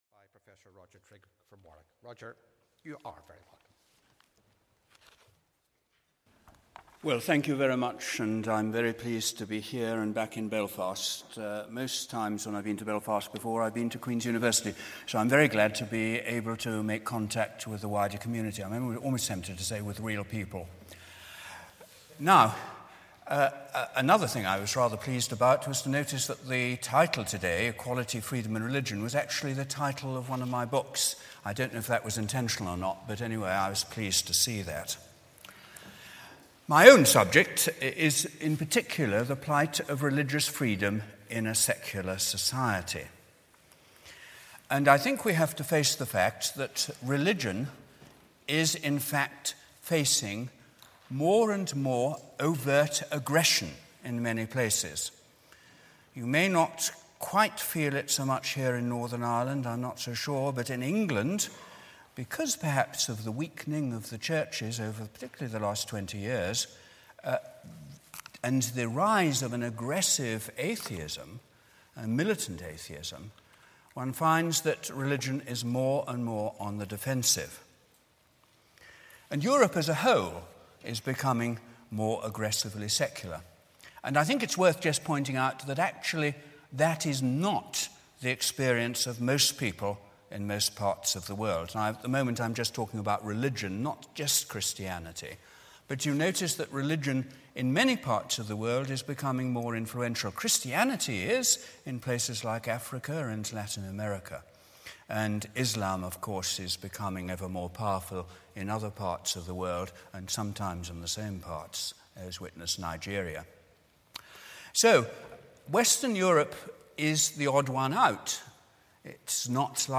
The Presbyterian Church in Ireland and Union Theological College held their second joint conference under the theme ‘The Church in the Public Square’ on Thursday, 9th October 2014.